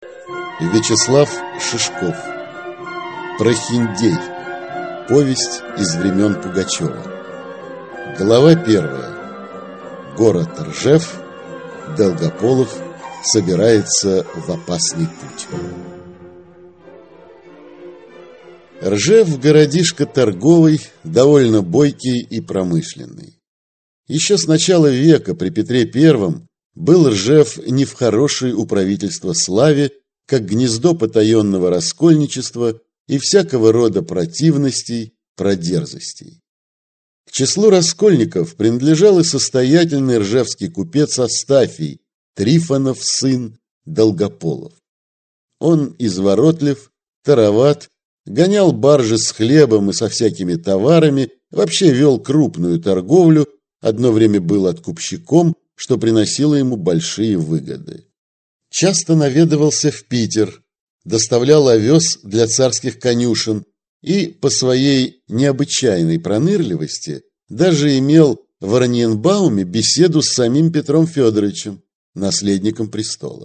Аудиокнига Прохиндей (повесть времен Пугачева) | Библиотека аудиокниг